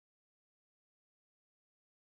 Silent.wav